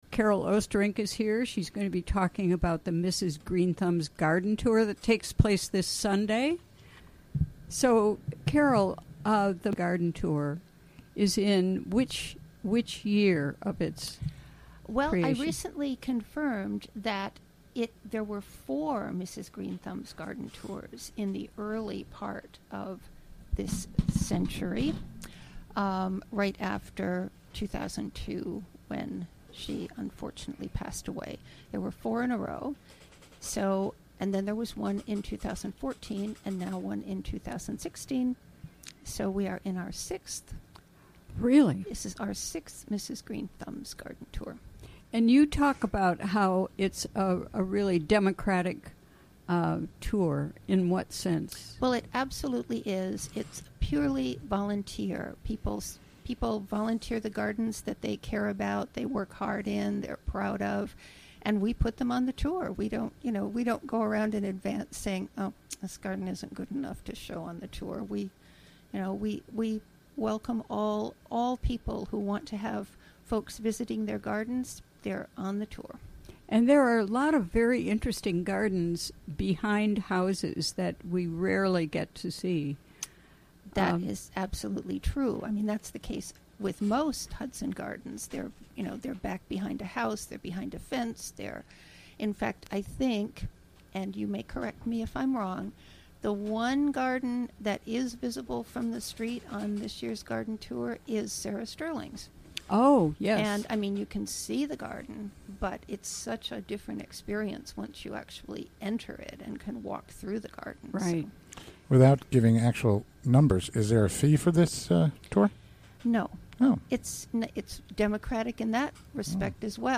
Recorded during the WGXC Afternoon Show on Thursday July 14, 2016.